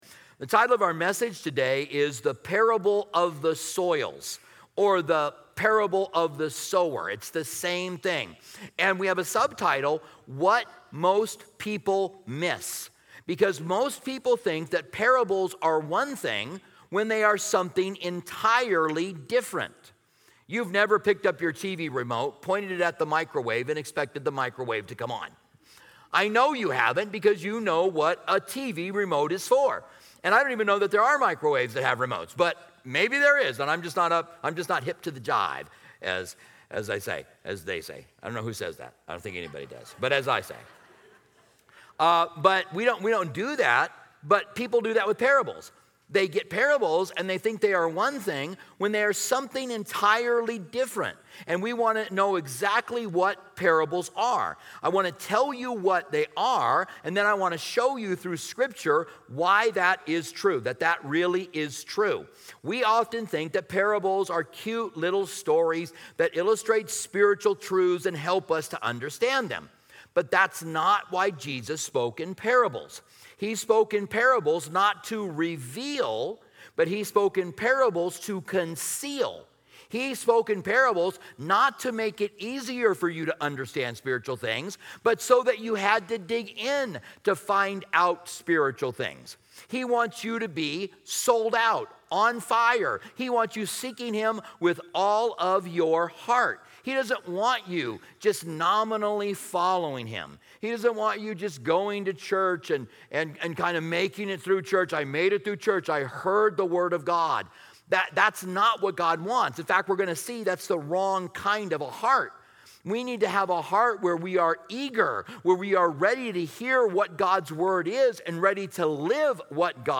This sermon explores the profound purpose of Jesus' parables, which were crafted to reveal truths to sincere seekers while concealing them from the hard-hearted. Discover the significance of the setting, the four types of soil representing different responses to God's Word, and the transformative potential of being receptive to His message.